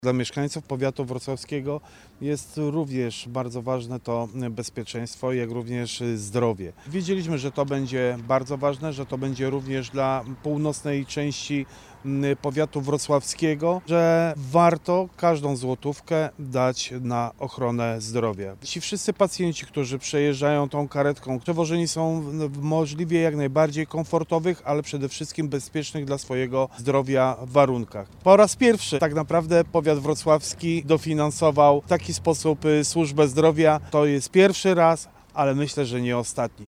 – To bardzo ważne wsparcie dla mieszkańców powiatu wrocławskiego – mówi Włodzimierz Chlebosz, Starosta Powiatu Wrocławskiego.